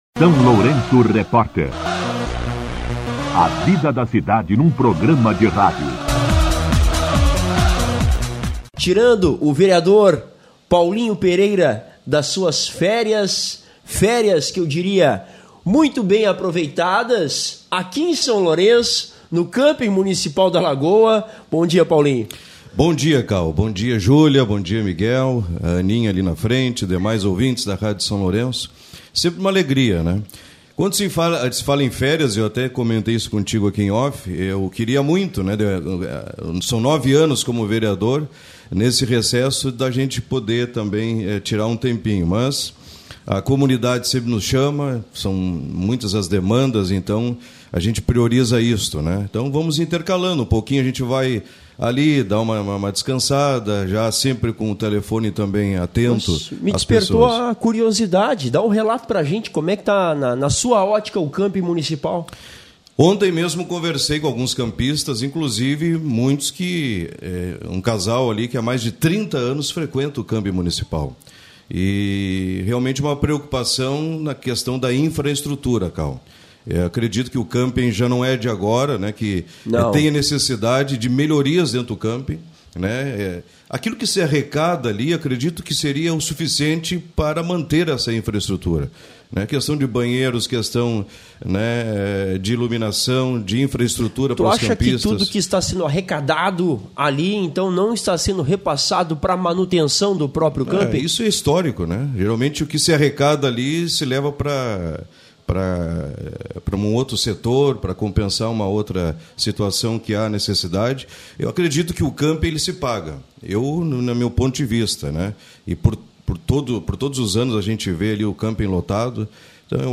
Entrevista com o vereador Paulinho Pereira (Podemos)
O vereador Paulinho Pereira (Podemos) concedeu entrevista ao SLR RÁDIO nesta sexta-feira (16), na qual cobrou maior atuação da Administração Municipal diante das demandas da comunidade, como a manutenção das vias, a limpeza da cidade e a ampliação do número de banheiros para veranistas.